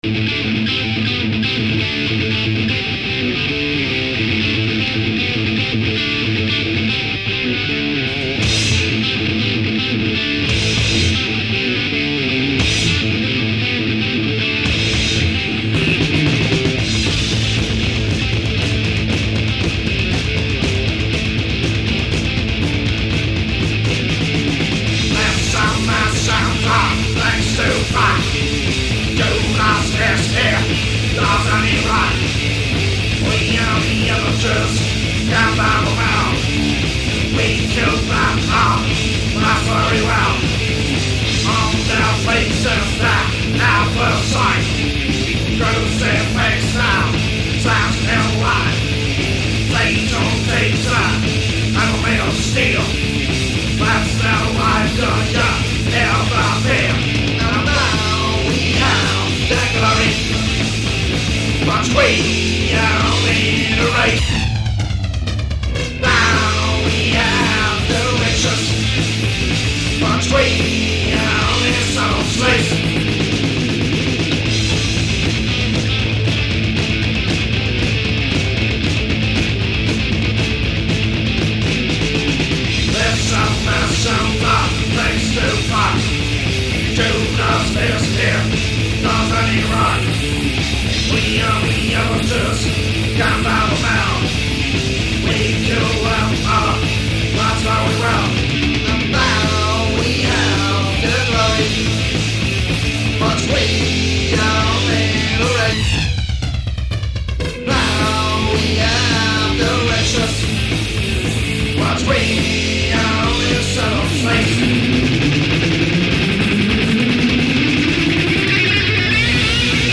stark von Speed- und Thrash-Metal beeinflusste Band